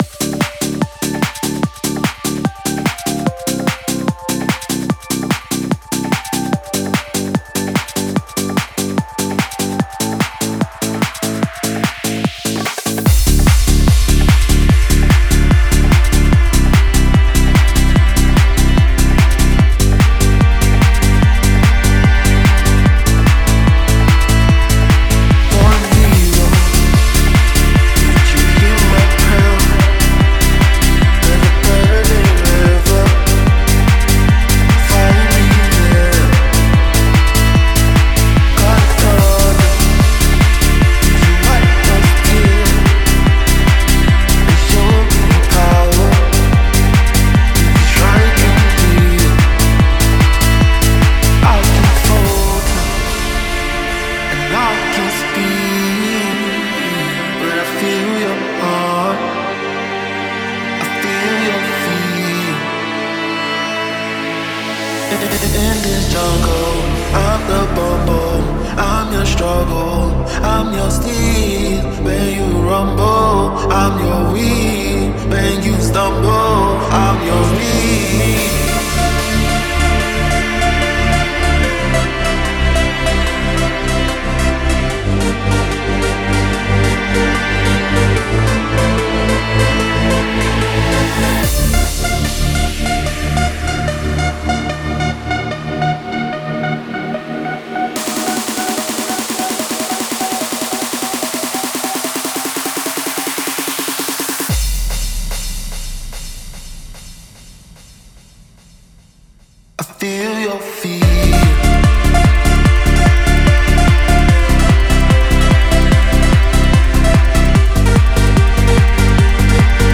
Electro Hard Techno